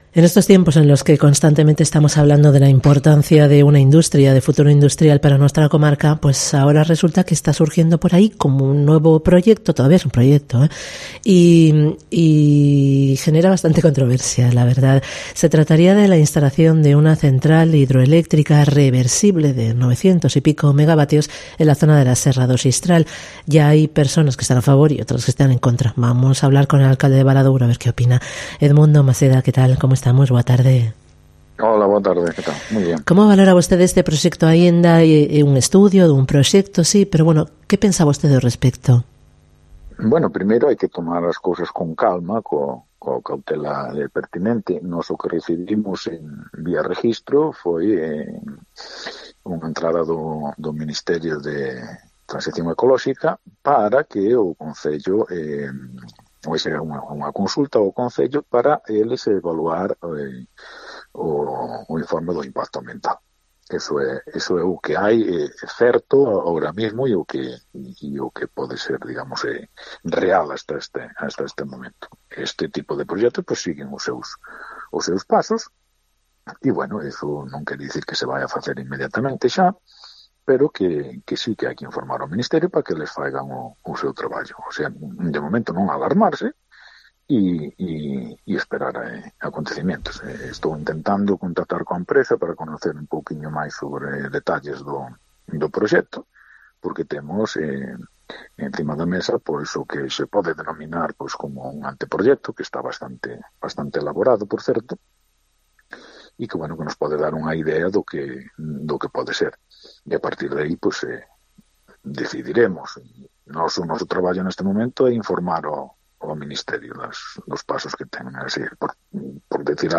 EDMUNDO MASEDA, alcalde de Valadouro, habla sobre el proyecto de Central Hidroeléctrica